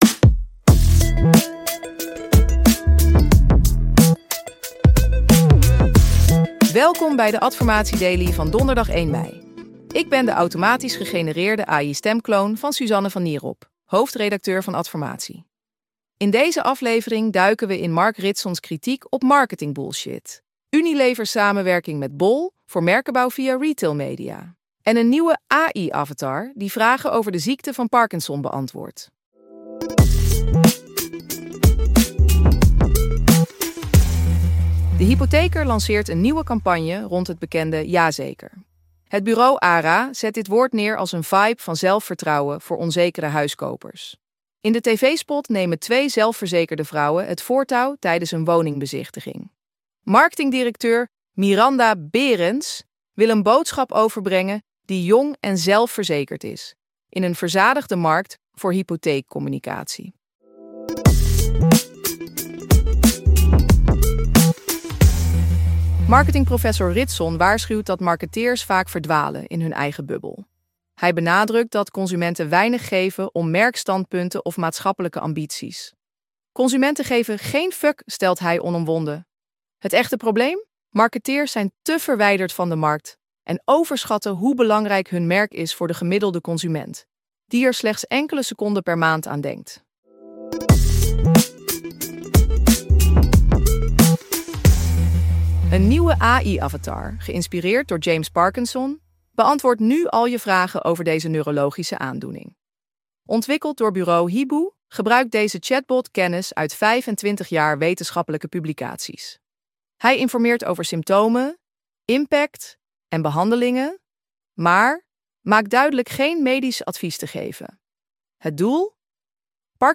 De Adformatie Daily wordt dagelijks automatisch gegenereerd met AI door EchoPod.